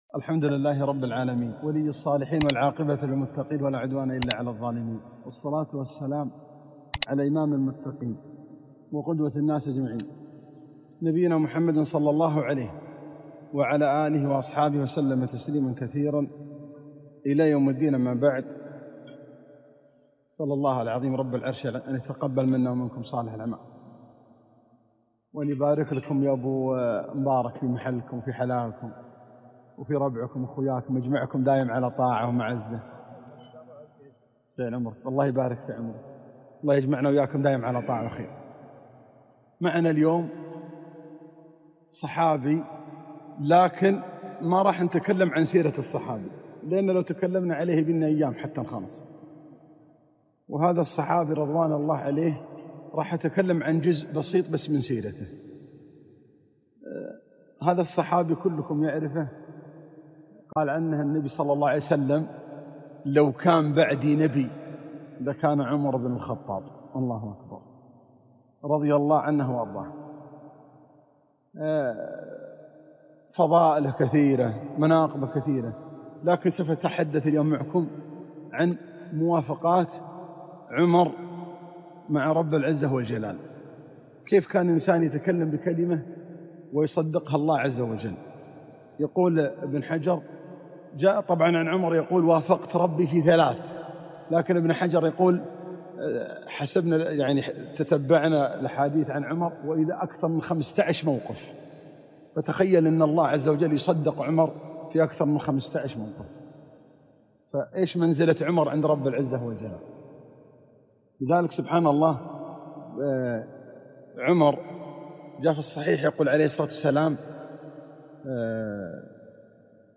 كلمة - موافقات الفاروق للقرآن رضي الله عنه